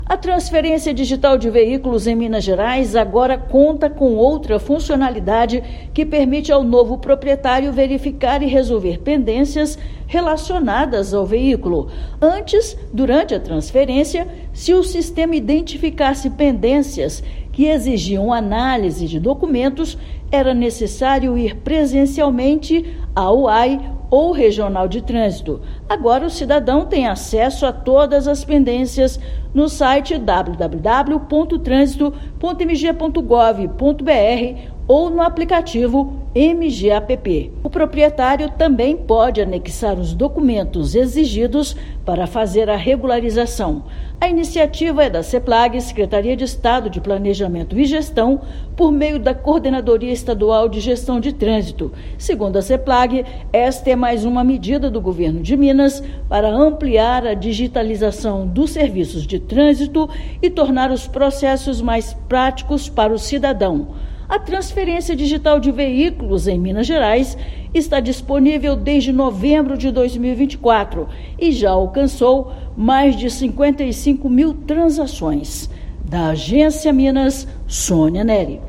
[RÁDIO] Governo de Minas digitaliza mais uma etapa da transferência de veículos para simplificar a regularização de pendências
Iniciativa da Seplag permite ao comprador resolver pendências do veículo sem sair de casa, pelo portal de Trânsito ou pelo aplicativo MG App. Ouça matéria de rádio.